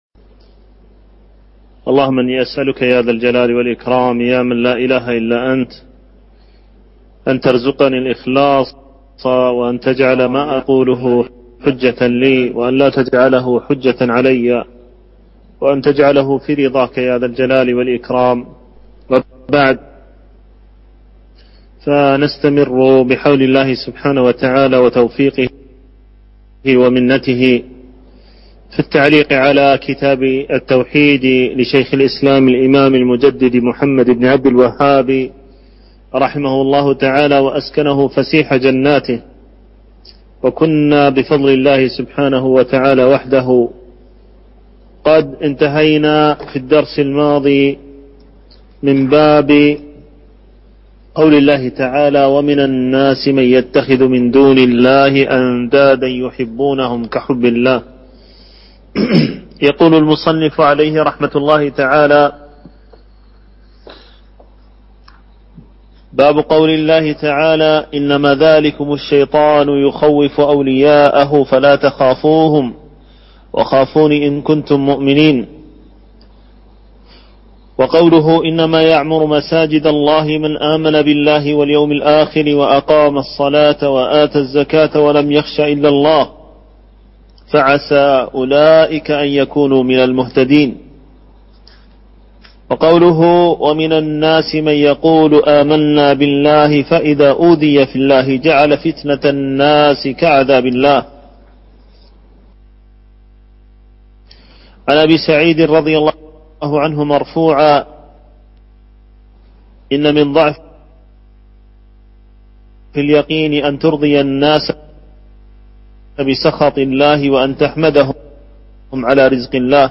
شرح كتاب التوحيد - الدرس الثامن والعشرون